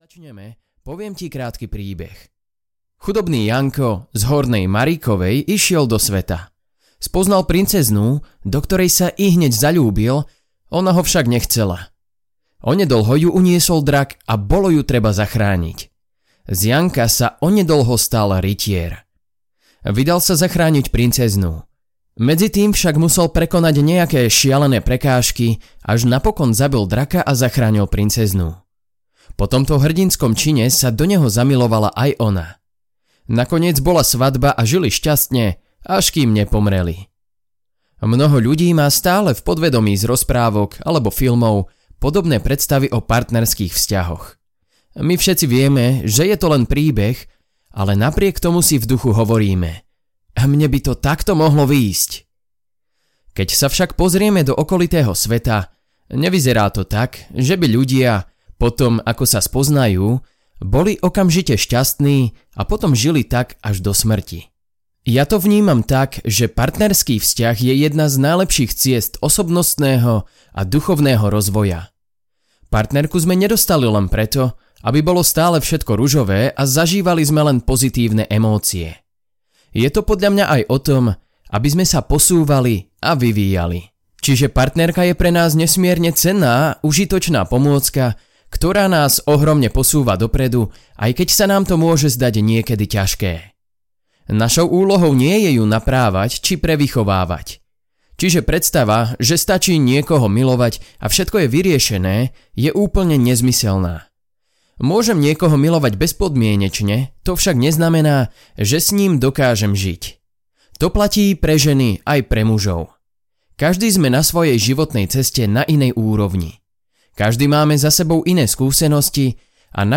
Ako ju pochopiť audiokniha
Ukázka z knihy